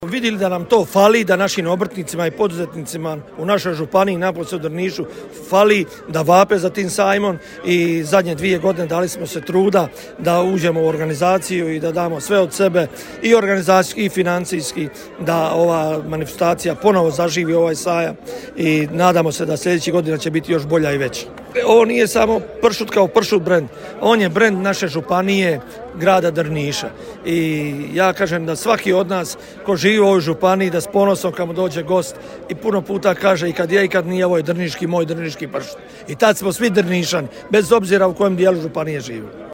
Večeras je u Drnišu svečano otvoren 8. Međunarodni festival pršuta, u organizaciji Turističke zajednice Grada Drniša te Grada Drniša.
Evo izjava: